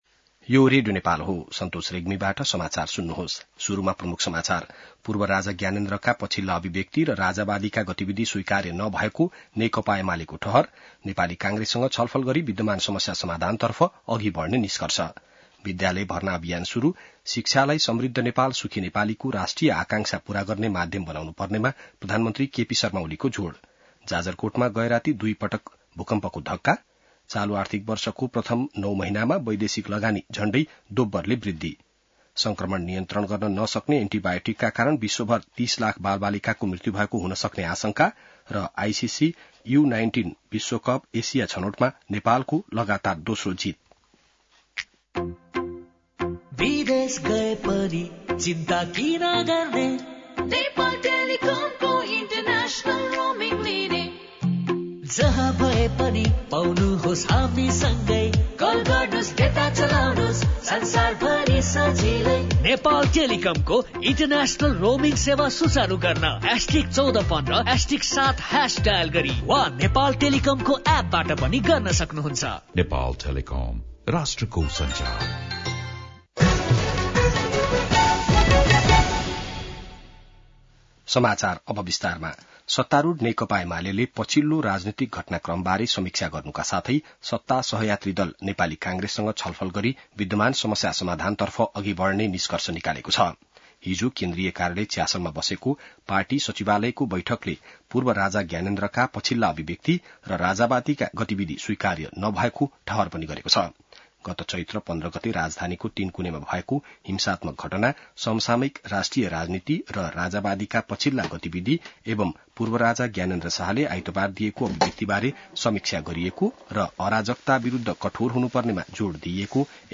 बिहान ७ बजेको नेपाली समाचार : २ वैशाख , २०८२